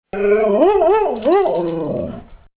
Remembering Daisy – The “Woo Woo” bark
One of the things that we loved about Daisy was her very distinctive bark. Her bark was not harsh and biting like so many dogs. It had soft, rounded edges and she only did it when she was excited and happy about something — like getting served her supper. The best way to describe it is that it sounded like, “Woo Woo!”
DaisyWooWoo.mp3